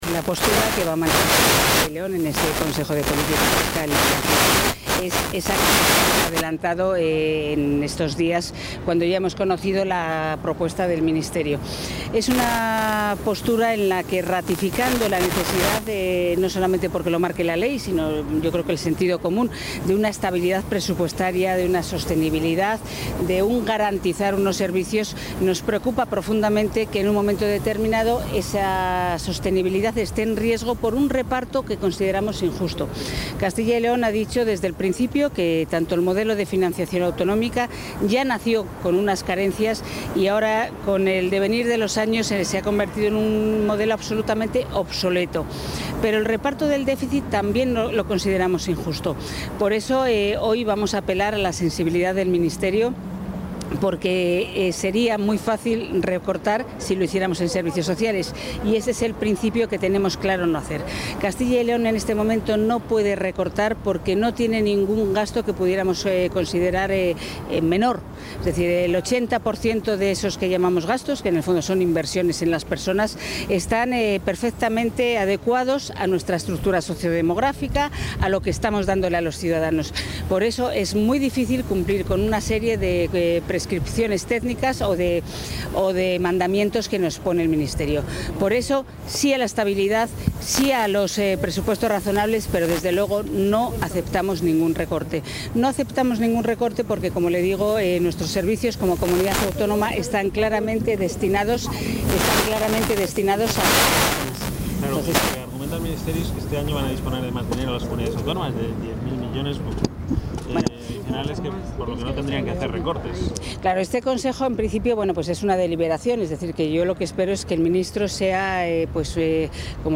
Declaraciones de la vicepresidenta de la Junta antes del Consejo de Política Fiscal y Financiera | Comunicación | Junta de Castilla y León
Se adjunta material audiovisual y gráfico con las declaraciones de la vicepresidenta y portavoz de la Junta, Rosa Valdeón, antes de asistir a la reunión del Consejo de Política Fiscal y Financiera.